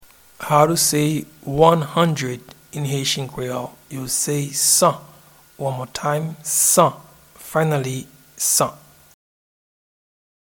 Pronunciation and Transcript:
One-hundred-in-Haitian-Creole-San.mp3